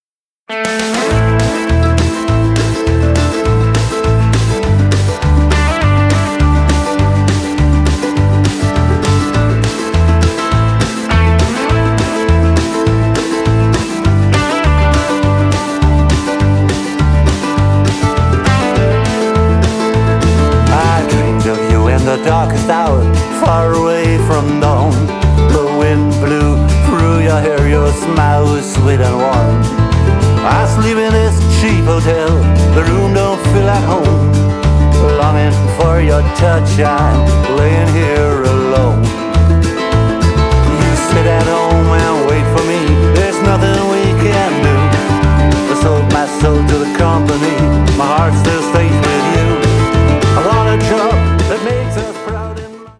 My journey towards Americana continues…
Vocals and harp
Drums, keyboards, string arrangement